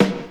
• '00s Hip-Hop Snare One Shot G Key 403.wav
Royality free snare sound tuned to the G note. Loudest frequency: 742Hz
00s-hip-hop-snare-one-shot-g-key-403-nUh.wav